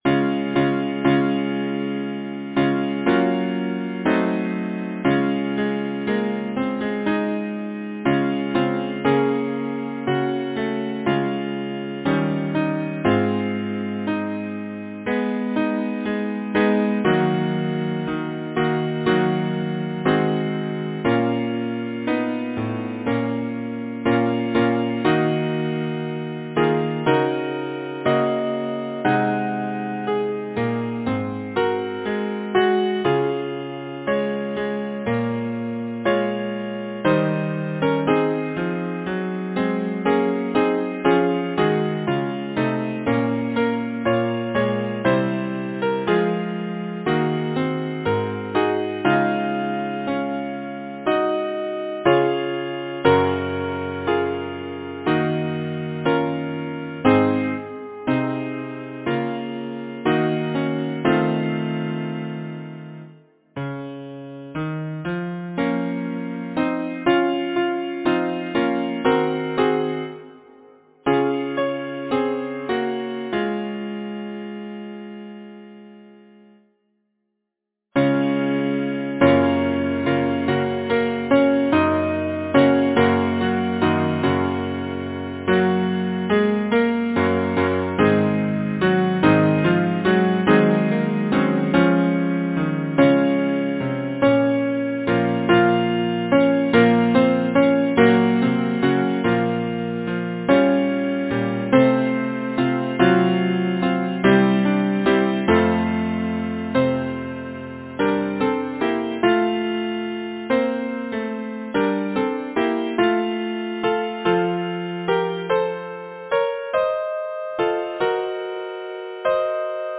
Language: English Instruments: A cappella